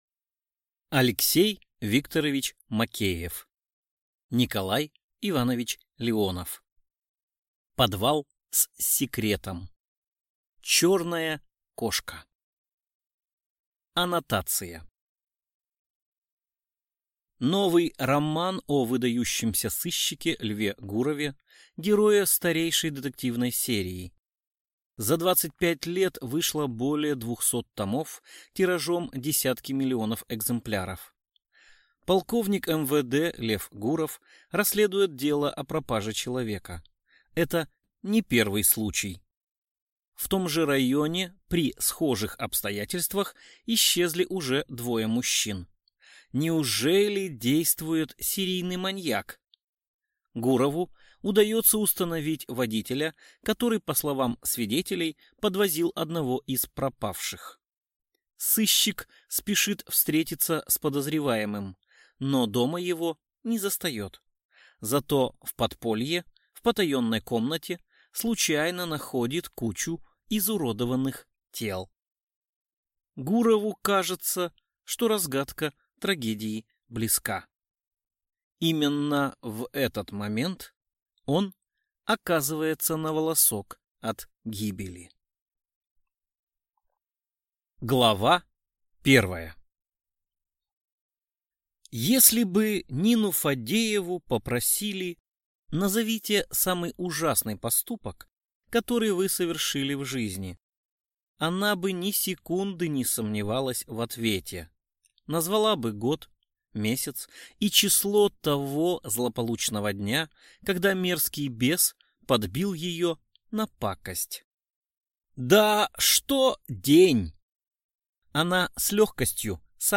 Аудиокнига Подвал с секретом | Библиотека аудиокниг